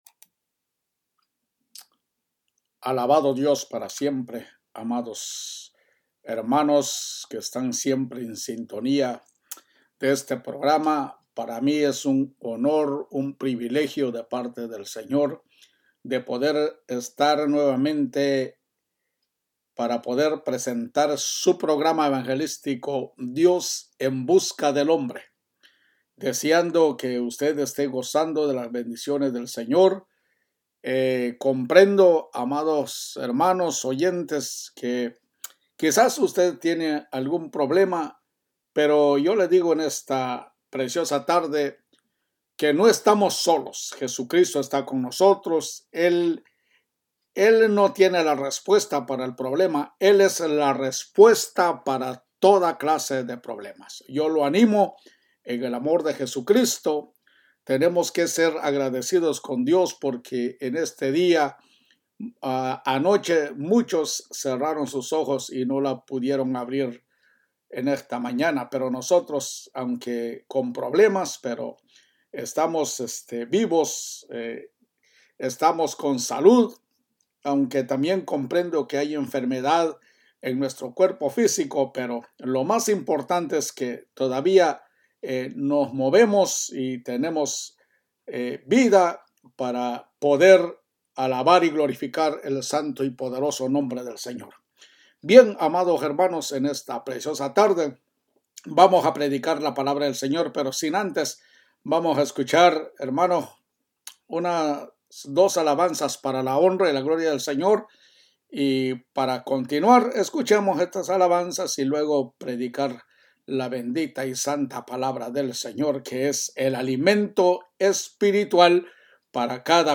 COMO LADRON EN LA NOCHE PREDICA #11